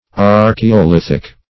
Search Result for " archaeolithic" : The Collaborative International Dictionary of English v.0.48: Archaeolithic \Ar`ch[ae]*o*lith"ic\ ([aum]r`k[-e]*[-o]*l[i^]th"[i^]k), a. [Gr.
archaeolithic.mp3